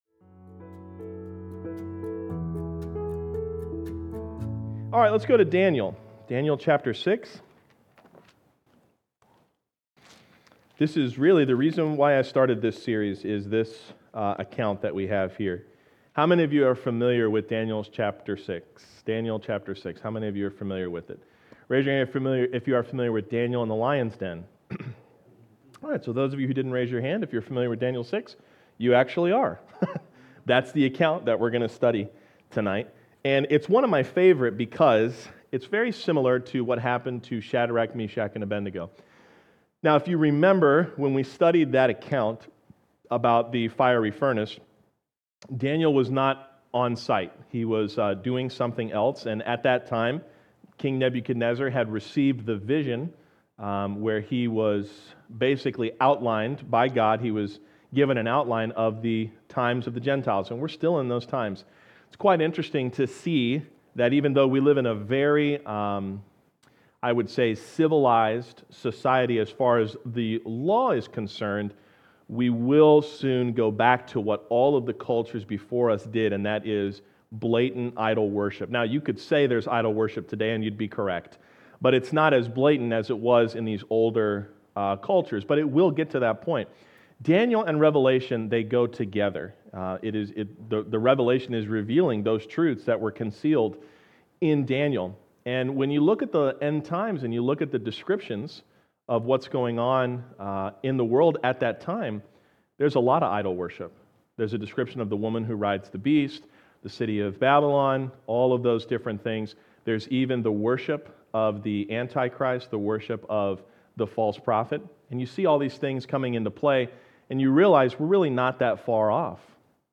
Daniel Chapter 6 | Into the Lion’s Den | Sermon Series